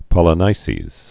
(pŏlə-nīsēz)